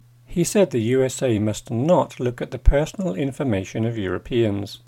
DICTATION 4